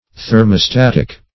Thermostatic \Ther`mo*stat"ic\, a.